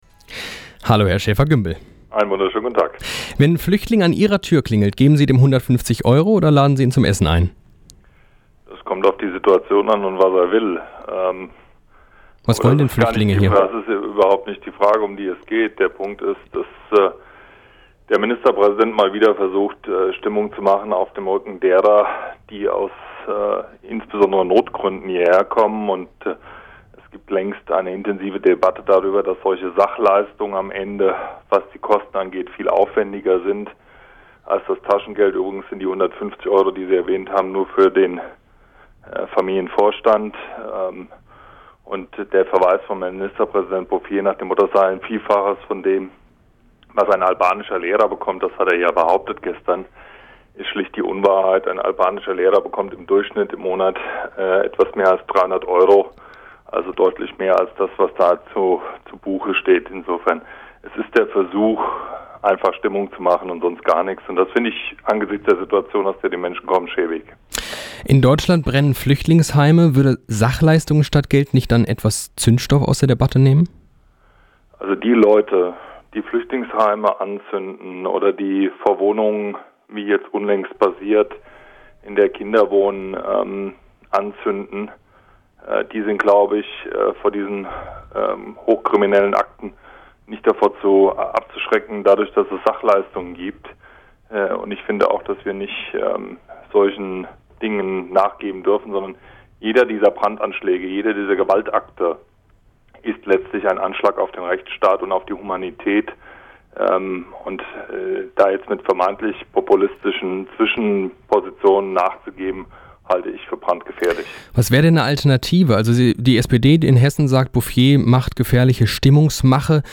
Interview: Thorsten Schäfer-Gümbel über die Flüchtlingspolititk in Hessen